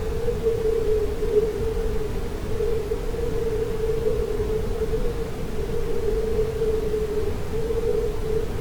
stick-the-quick/audio/ambience/howling_wind.ogg at 3b0b3bbaf440836ff10095e0aa86d6a9451ee023
howling_wind.ogg